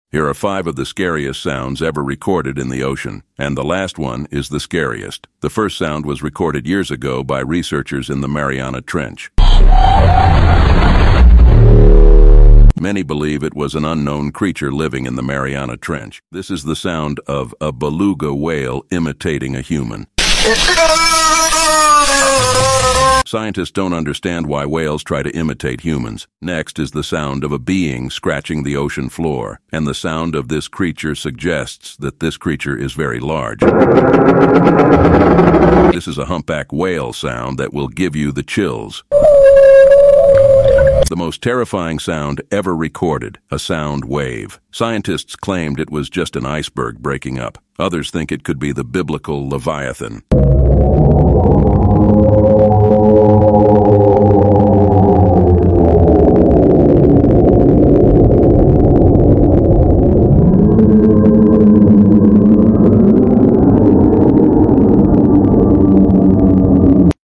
5 Scariest Ocean Sounds 🌊🔉Don’t sound effects free download